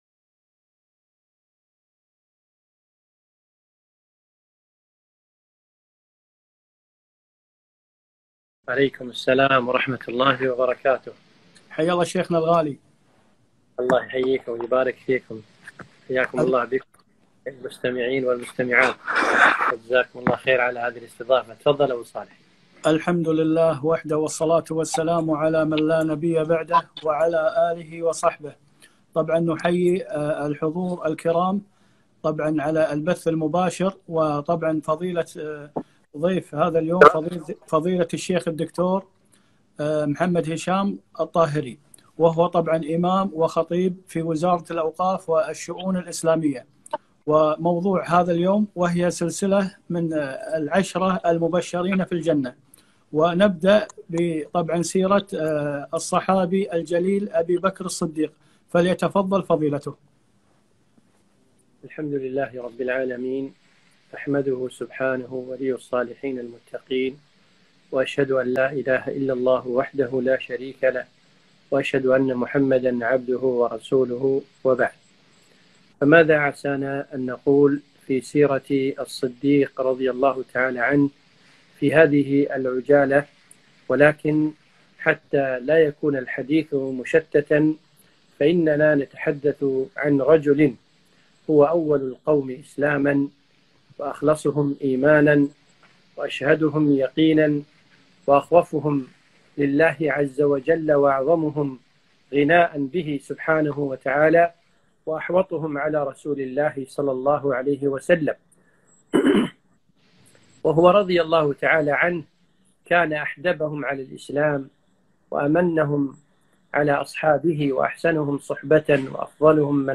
محاضرة - أبو بكر الصديق - رضي الله عنه